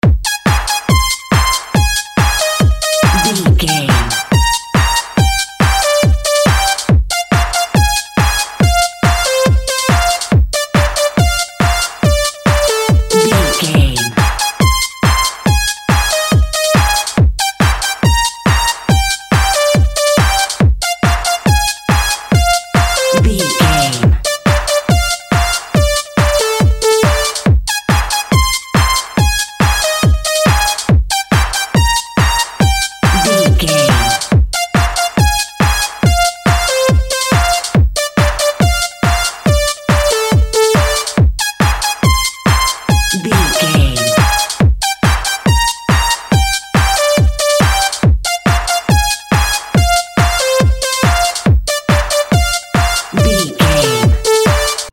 Ionian/Major
A♭
Fast
energetic
high tech
uplifting
hypnotic
industrial
synthesiser
drum machine
electro
synth lead
synth bass
Electronic drums
Synth pads